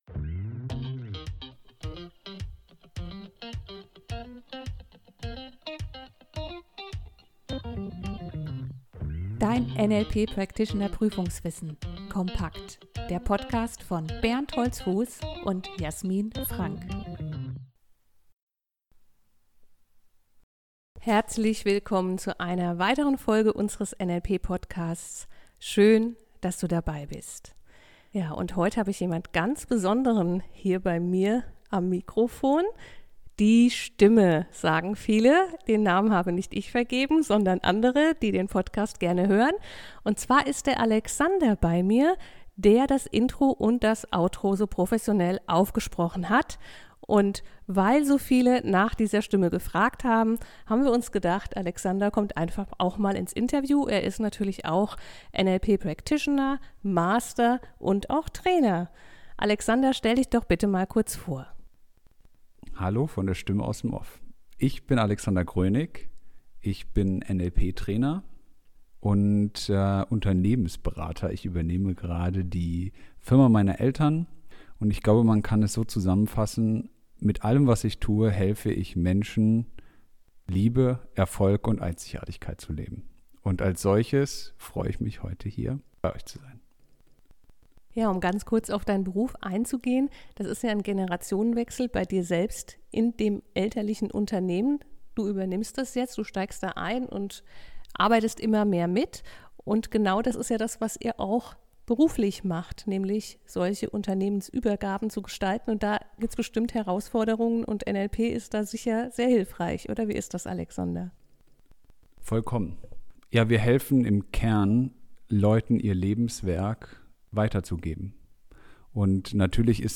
NLP Interview